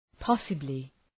Προφορά
{‘pɒsəblı}
possibly.mp3